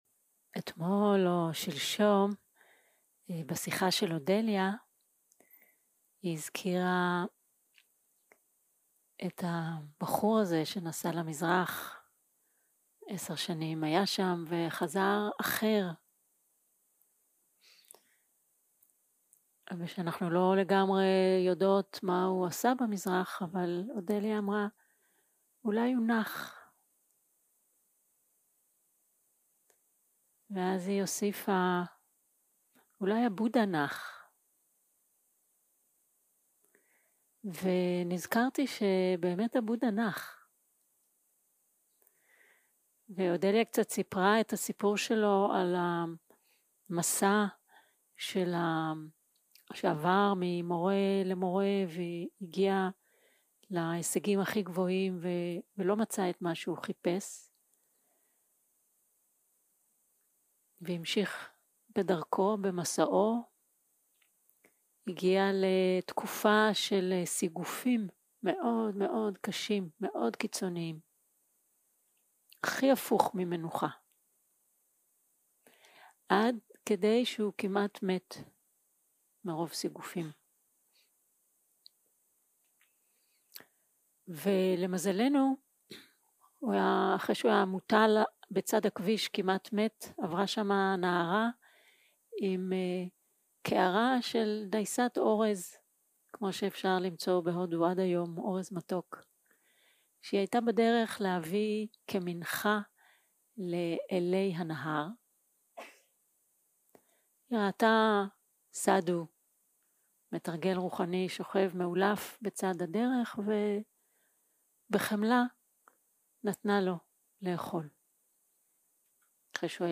יום 5 - הקלטה 11 - בוקר - הנחיות למדיטציה
סוג ההקלטה: שיחת הנחיות למדיטציה